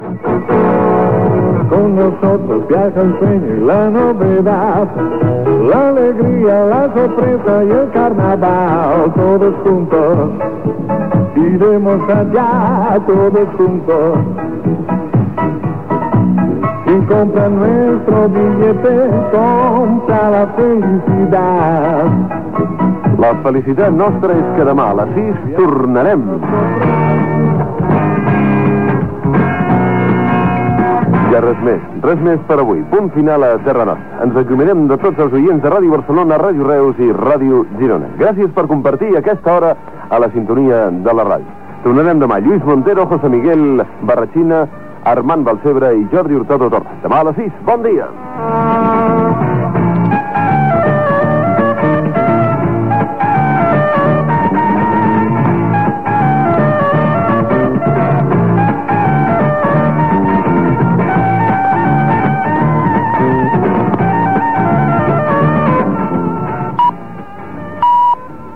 Comiat del programa
Entreteniment